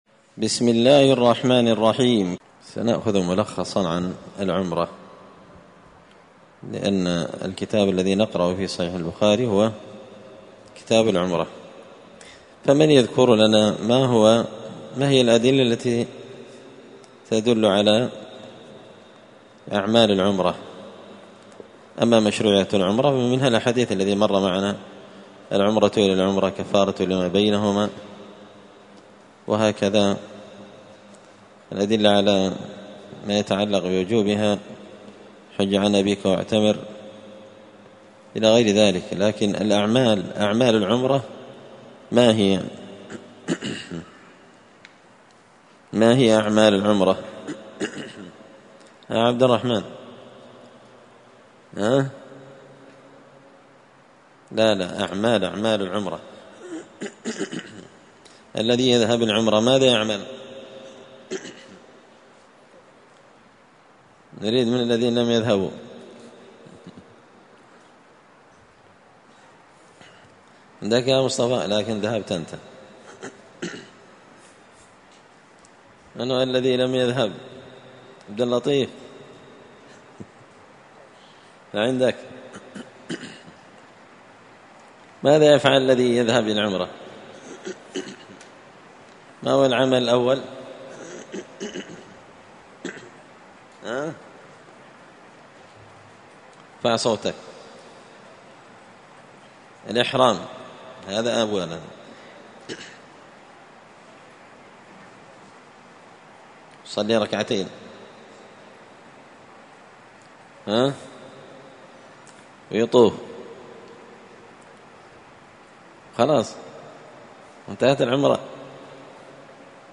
السبت 27 ذو الحجة 1444 هــــ | الدروس | شارك بتعليقك | 15 المشاهدات